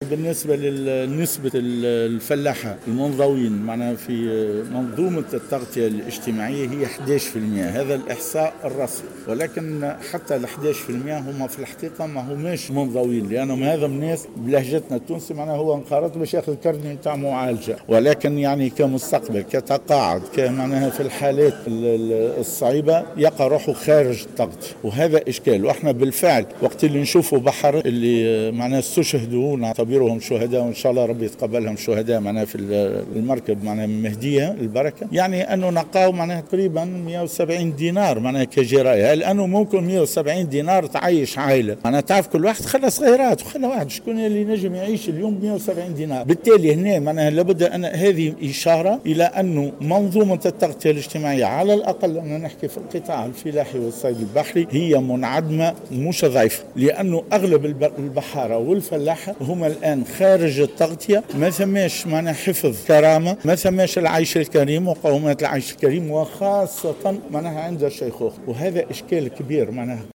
تصرح لمراسل الجوهرة اف ام